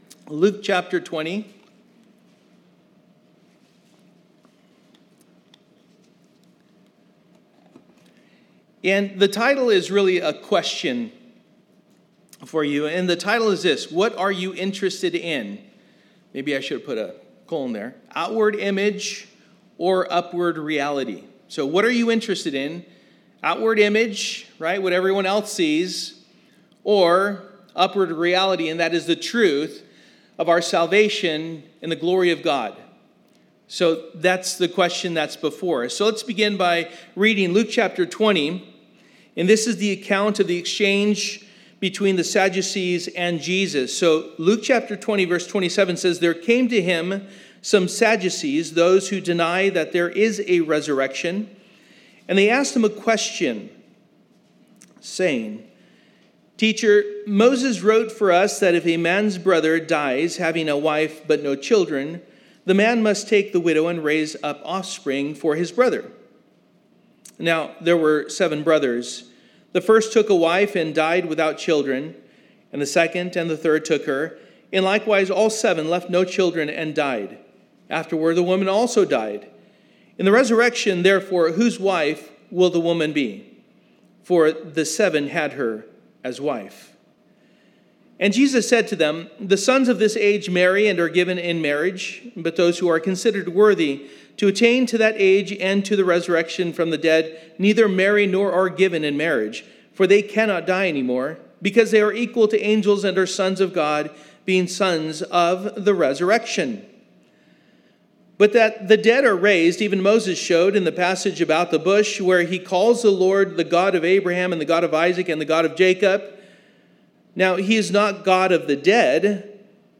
The Gospel of Luke Passage: Luke 20:27-47 Service: Sunday Morning « Psalm 17 Life and Death »